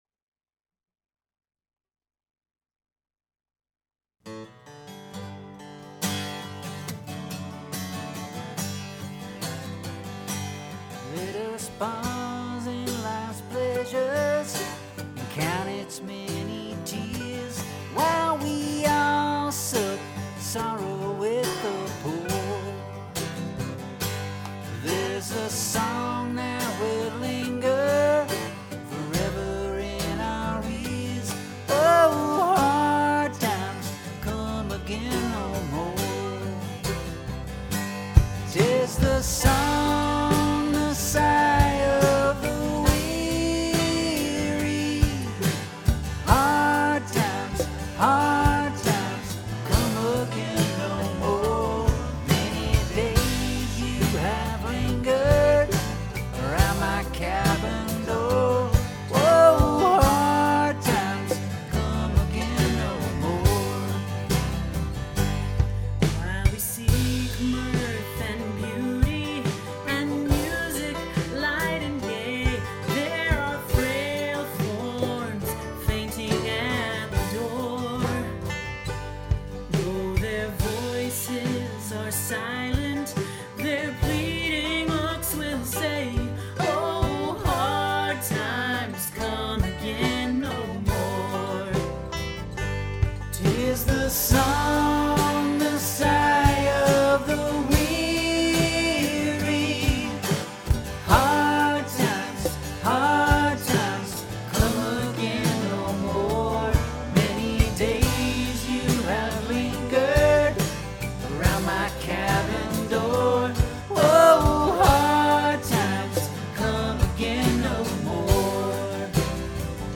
After that, many fellow musicians submitted their tracks by recording them in their homes with whatever technology they had under the “shelter-in-place” directive of the time.
Musicians
Traveling Murphys, TheGroup Vocals Chorus 3 and 4